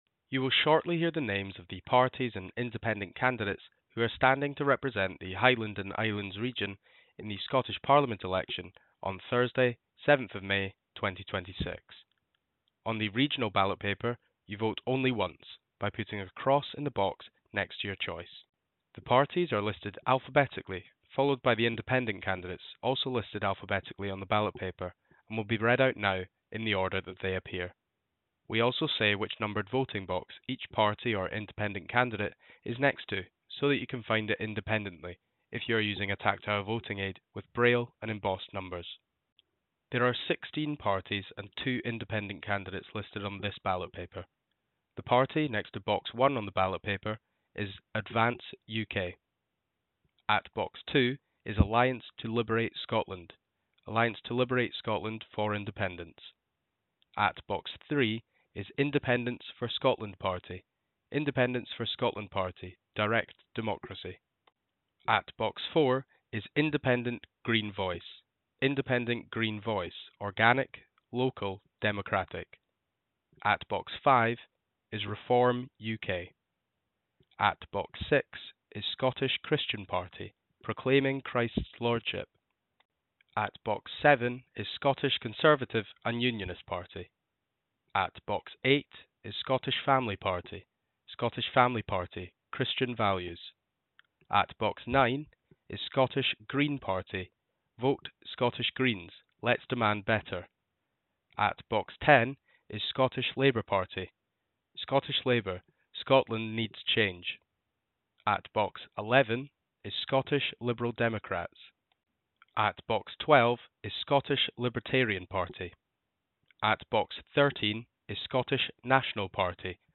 Audio recordings of the text and layout on ballot papers used in the 2026 Scottish Parliament election for the Shetland Islands Constituency and the Highlands and Islands Region.
spoken-ballot-paper-regional